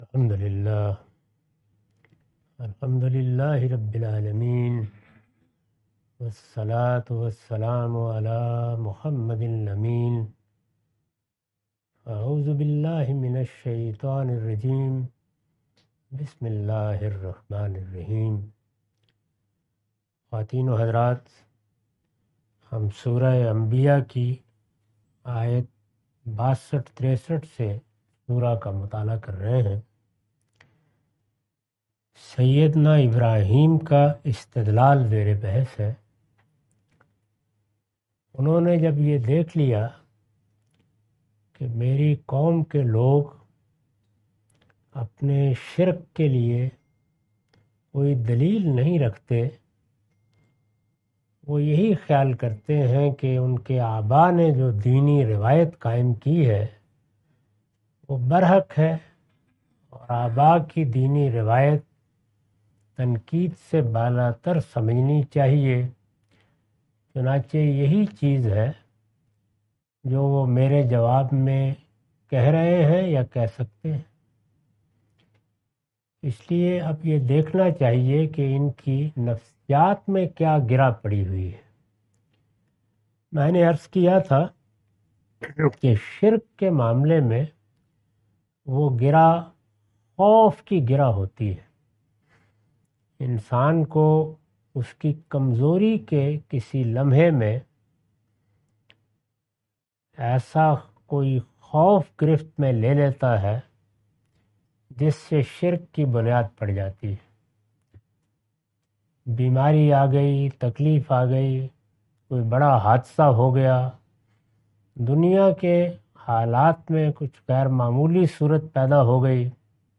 Surah Al-Anbiya A lecture of Tafseer-ul-Quran – Al-Bayan by Javed Ahmad Ghamidi. Commentary and explanation of verses 63-69.